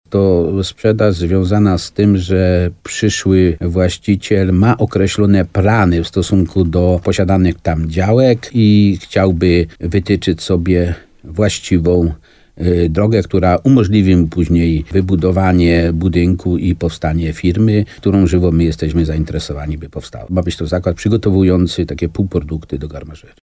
– To tereny na pograniczu z miejscowością Myślec, które chce przejąć przedsiębiorca ze Szczawnicy – mówił w programie Słowo za Słowo na antenie RDN Nowy Sącz, wójt gminy Nawojowa Stanisław Kiełbasa.